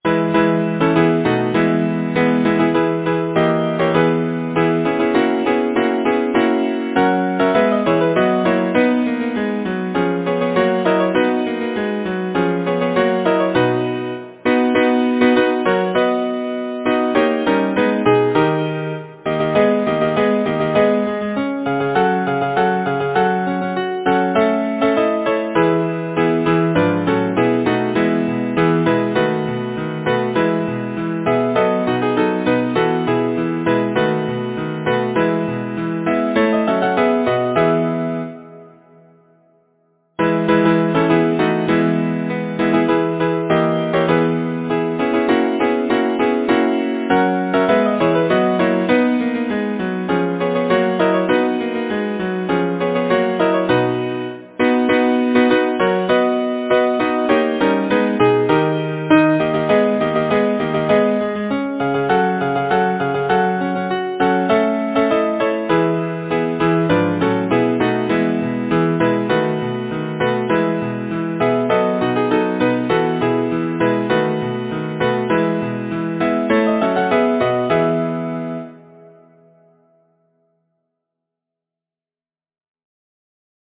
Title: Song for Spring Composer: Edouard Silas Lyricist: Anonymous Number of voices: 4vv Voicing: SATB Genre: Secular, Partsong
Language: English Instruments: A cappella